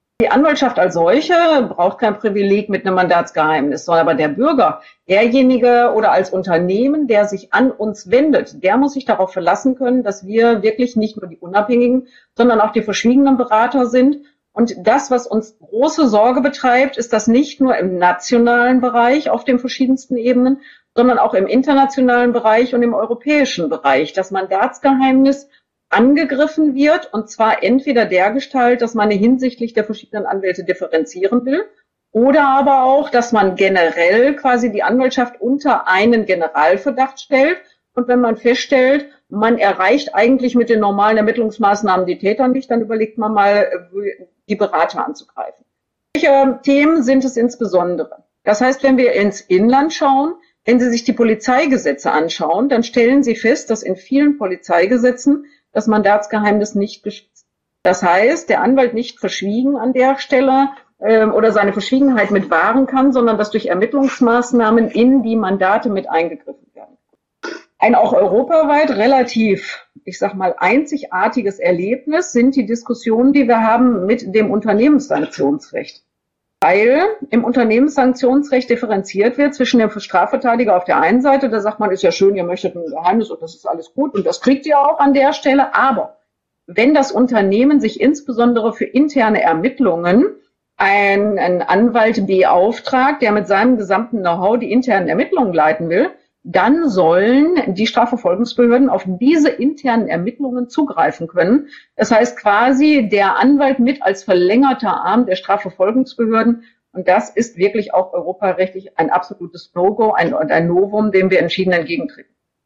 DAV, O-Töne / Radiobeiträge, Recht, , , , , ,